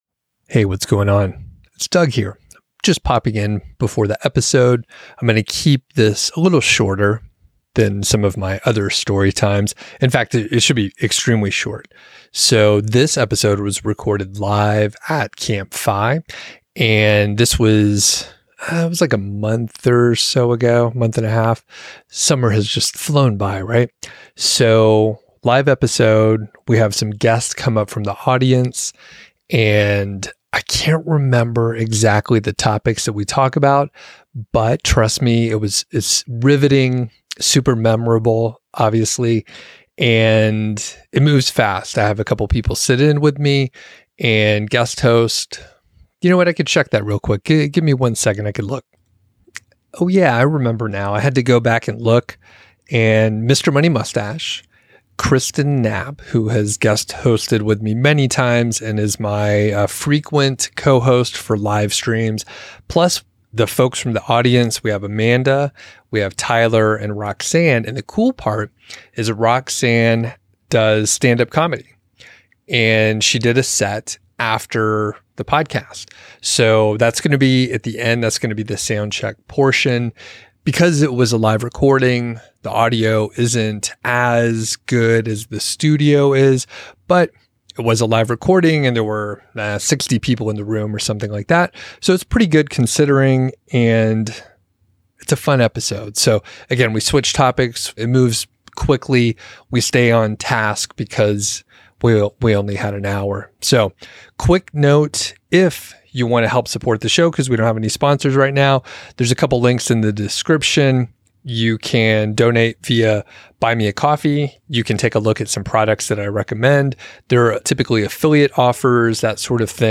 Mr. Money Mustache Defines Cheap vs. Frugal (Live CampFI) | MHFI 283